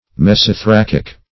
Mesothoracic \Mes`o*tho*rac"ic\